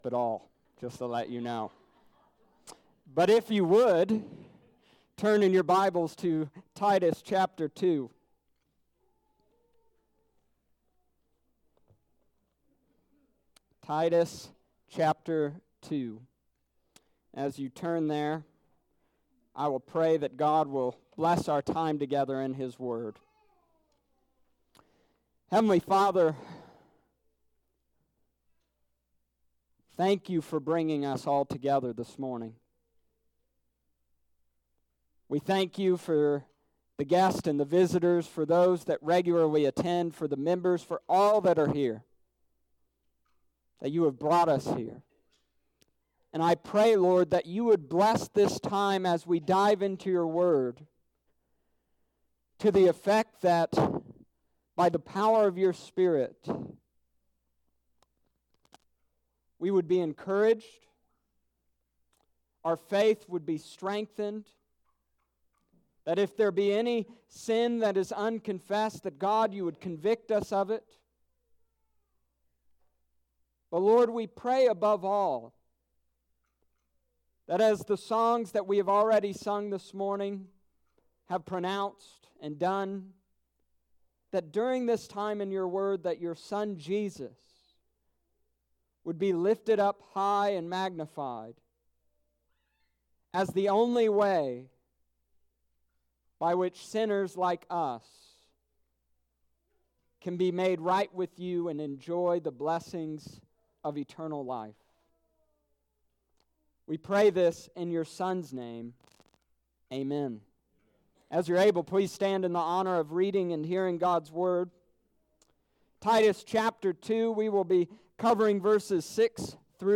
The Product of the Gospel Part 2 Titus Chapter 2 verses 6-10 Sunday Morning service Nov 3rd 2019